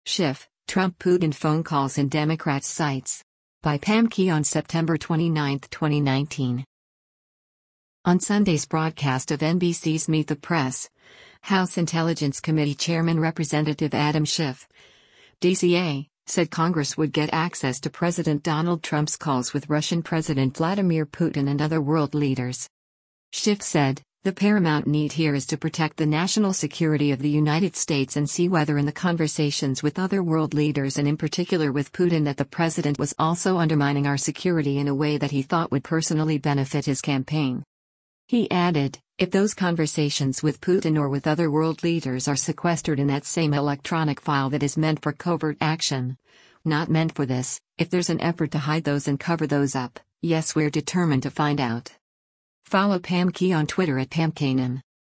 On Sunday’s broadcast of NBC’s “Meet the Press,” House Intelligence Committee chairman Rep. Adam Schiff (D-CA) said Congress would get access to President Donald Trump’s calls with Russian President Vladimir Putin and other world leaders.